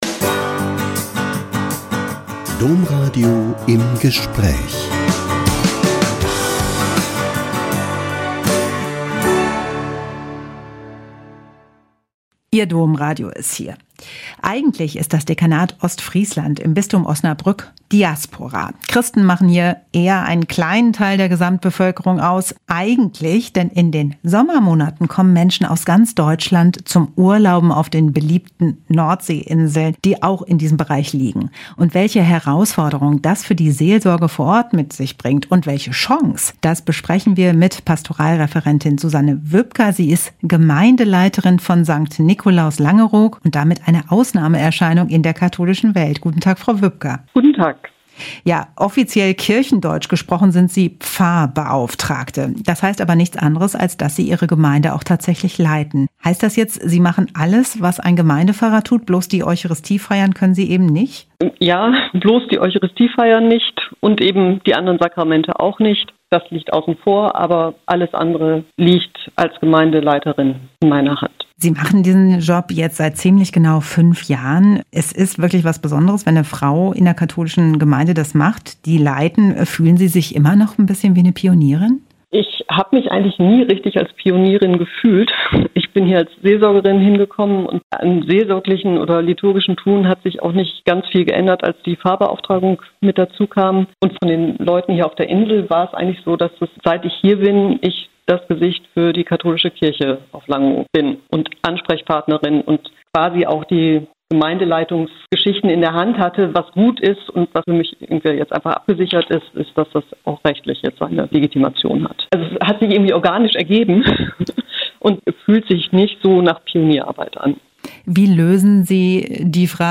~ Im Gespräch Podcast